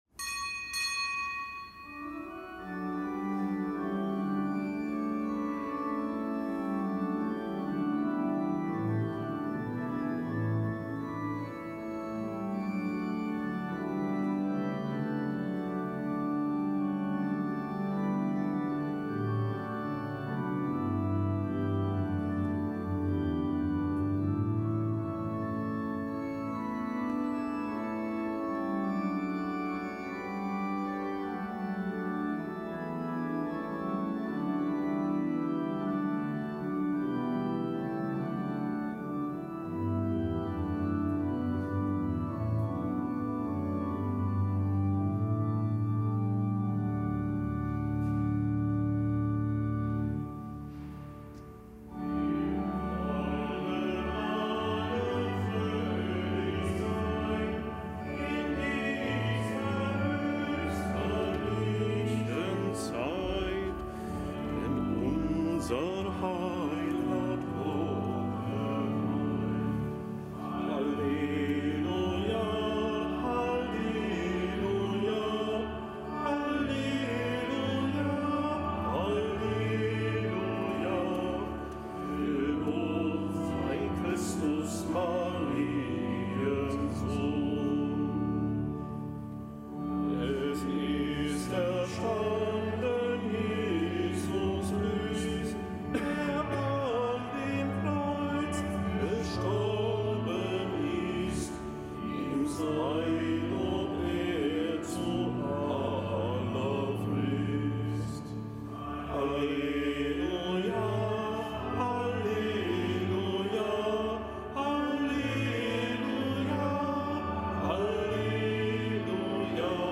Kapitelsmesse aus dem Kölner Dom am Mittwoch der Osteroktav